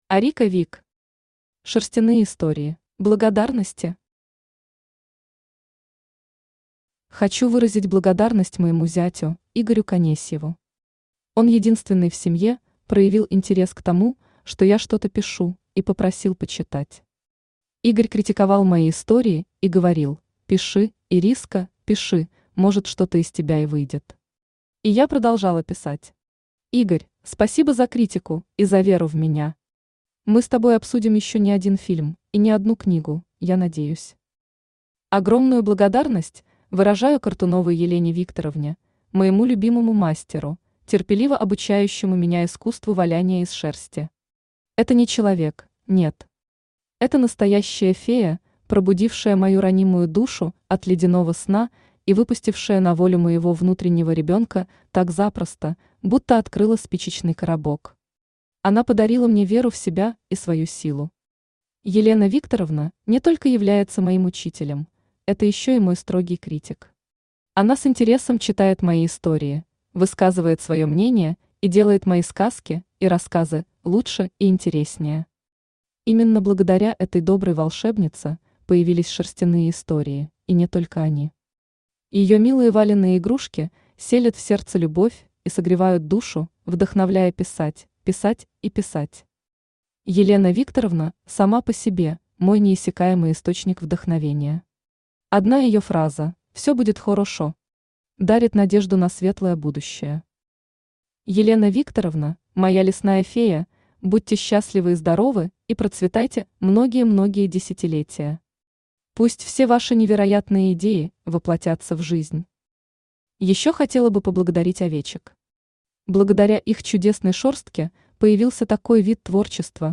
Aудиокнига Шерстяные истории Автор Арика Вик Читает аудиокнигу Авточтец ЛитРес.